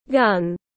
Khẩu súng tiếng anh gọi là gun, phiên âm tiếng anh đọc là /ɡʌn/